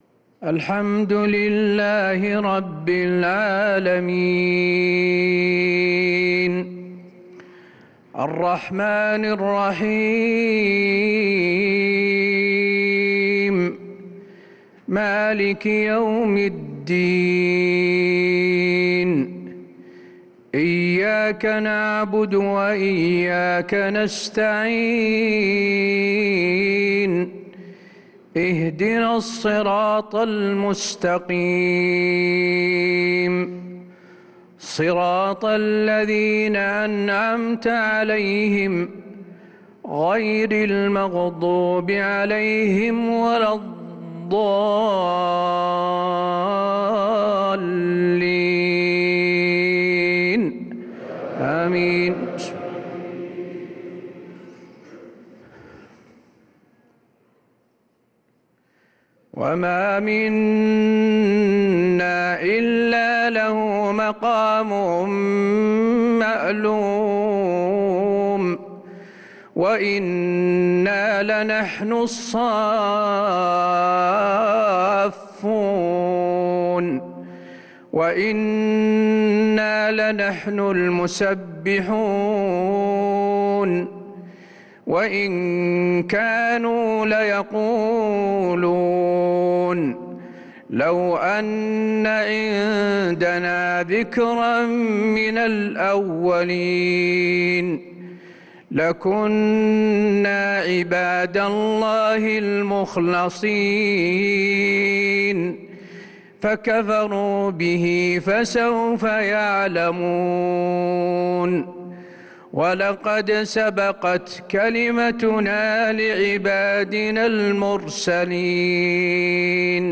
عشاء السبت 8-9-1446هـ خواتيم سورة الصافات 164-182 و العصر كاملة | Isha prayer from Surat as-Saffat & al-`Asr 8-3-2025 > 1446 🕌 > الفروض - تلاوات الحرمين